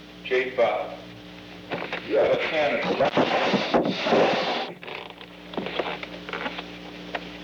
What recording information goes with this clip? The Old Executive Office Building taping system captured this recording, which is known as Conversation 314-010 of the White House Tapes.